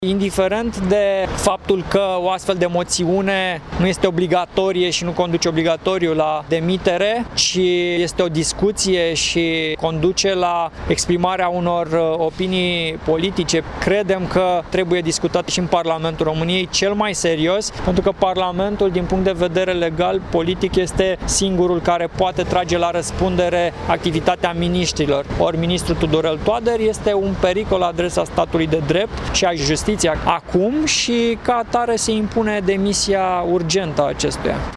Uniunea Salvaţi România (USR) a anunţat că a făcut o evaluare proprie a ministrului justiţiei, Tudorel Toader, şi că va depune o moţiune simplă împotriva acestuia, în prima zi a sesiunii parlamentare. Deputatul Stelian Ion a precizat că ministrul Justiţiei trebuie să demisioneze din cauza prestaţiei necorespunzătoare şi că USR are sprijinul colegilor din opoziţie pentru moţiune.